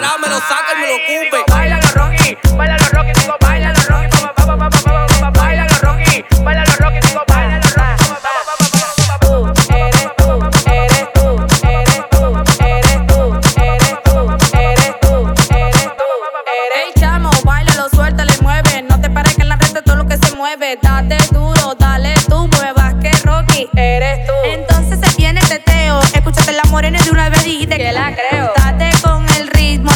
Urbano latino Latin
Жанр: Латино